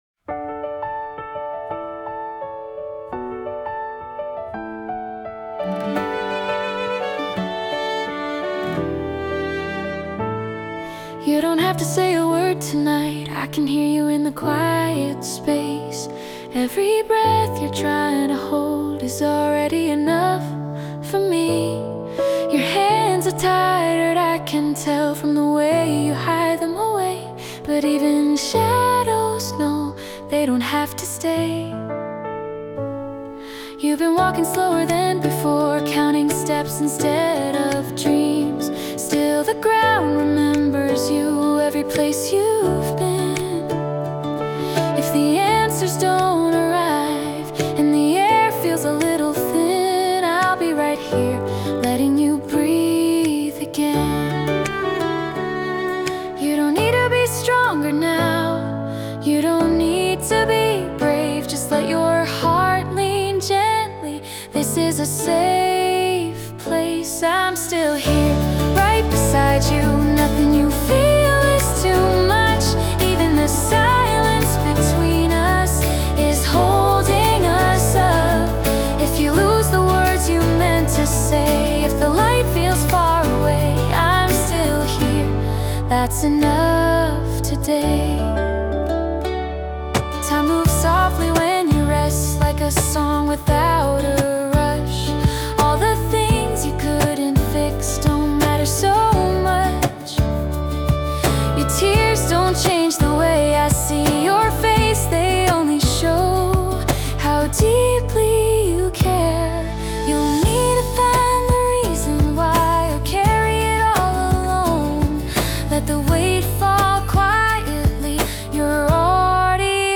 洋楽女性ボーカル著作権フリーBGM ボーカル
女性ボーカル（洋楽・英語）曲です。